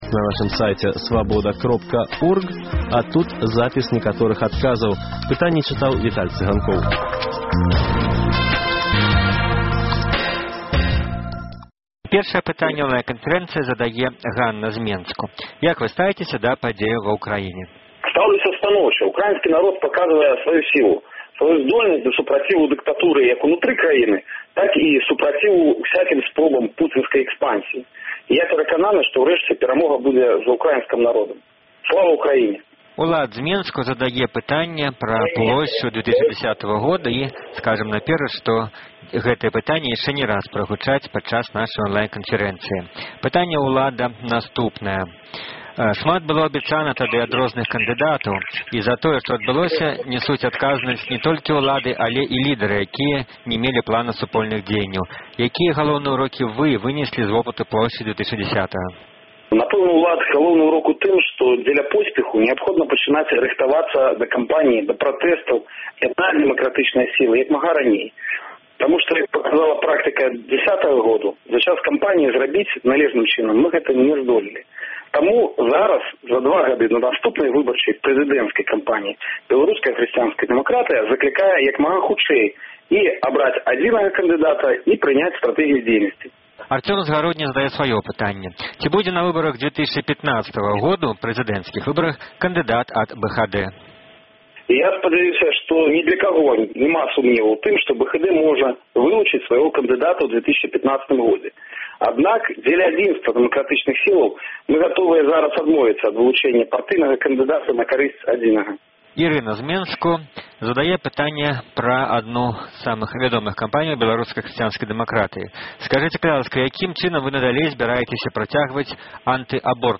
Онлайн-канфэрэнцыя з Віталём Рымашэўскім
Онлайн-канфэрэнцыя з былым кандыдатам у прэзыдэнты, сустаршынём Беларускай хрысьціянскай дэмакратыі Віталем Рымашэўскім.